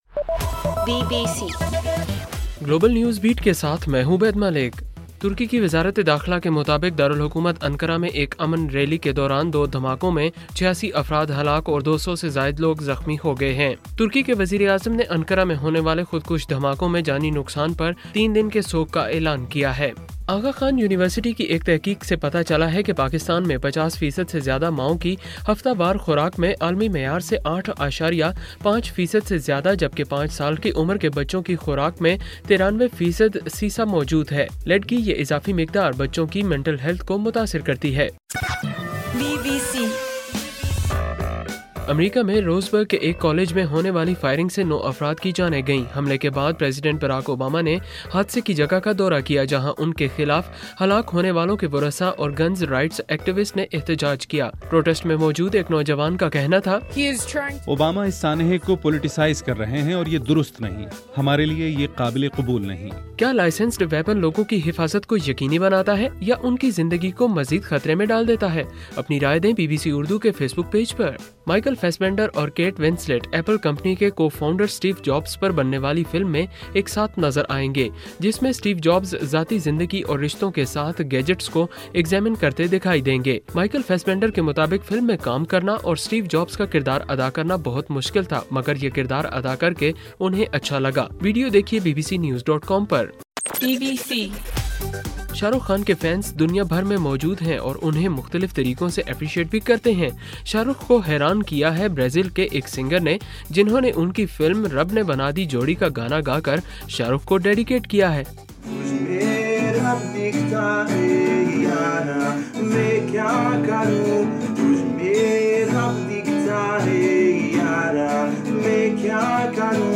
اکتوبر 10: رات 12 بجے کا گلوبل نیوز بیٹ بُلیٹن